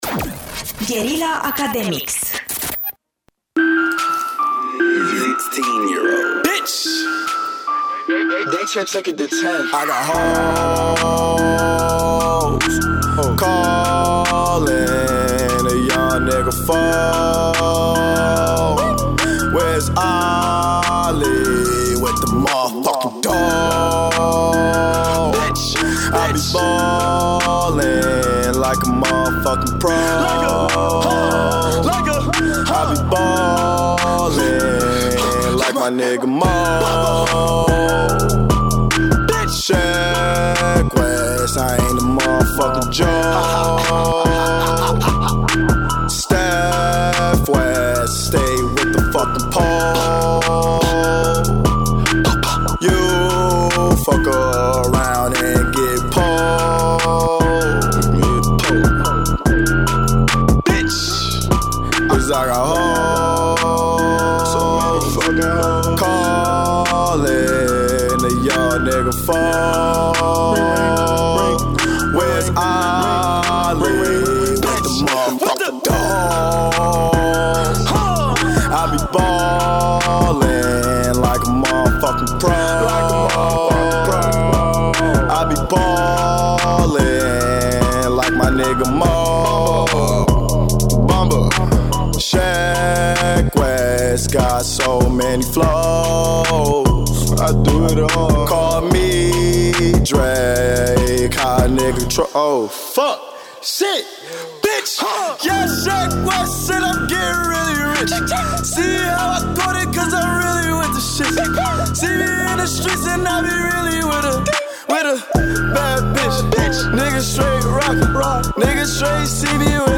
Academics este emisiunea de la Radio Guerrilla ce îi are drept gazde pe elevii din primele sezoane Uman Real, care acum au crescut și au devenit studenți.